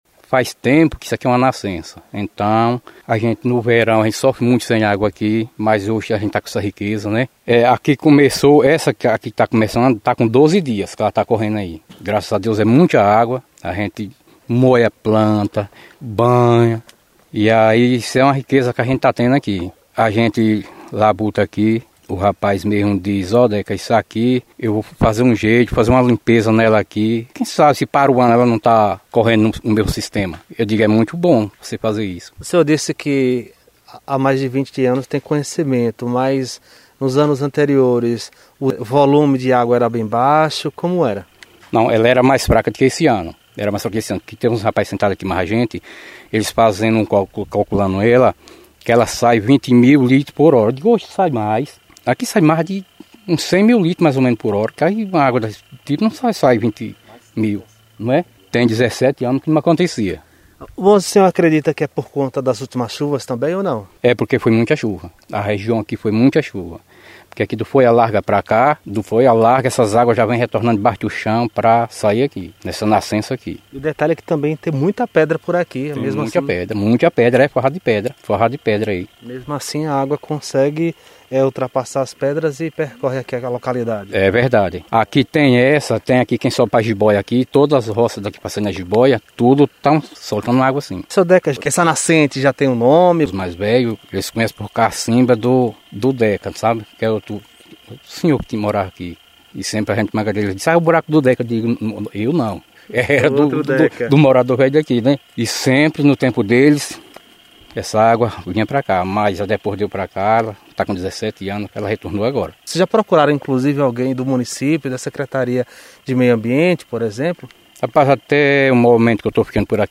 Reportagem: moradores do povoado de Morrinhos falam sobre os benefícios da nascente de rio